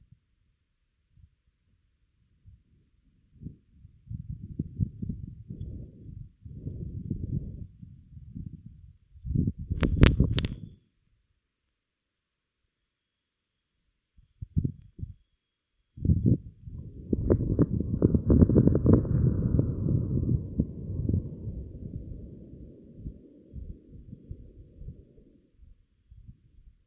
Ils ont été vus, ou plutôt entendus, de manière totalement fortuite, grâce au microphone de l’instrument SuperCam sur le rover Perseverance qui sillonne Mars depuis 2020.
Mais le dernier titre de cette compilation d’un autre monde est une pépite : un dust devil capté en direct alors qu’il passait au-dessus de notre microphone.
Le son du tout premier éclair enregistré sur Mars.
discharges-dust-devils-1.mp3